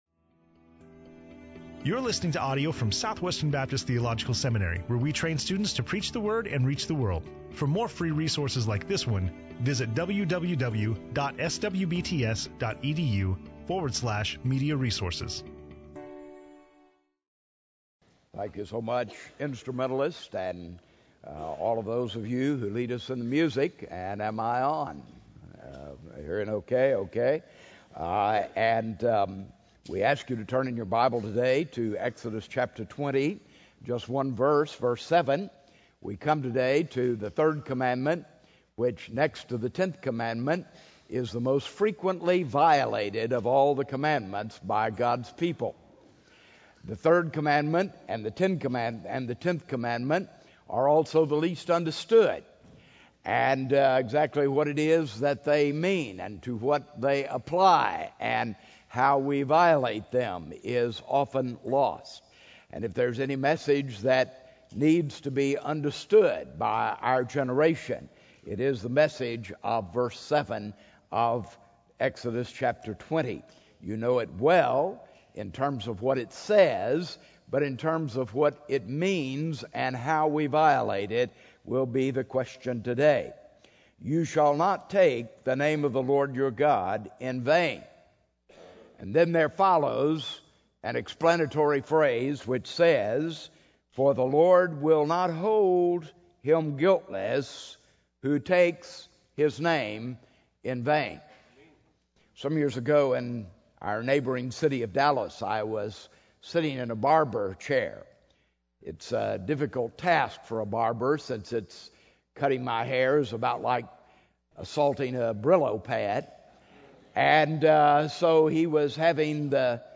SWBTS Chapel